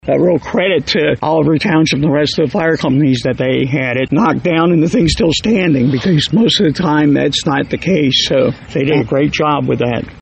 was on site and described the event.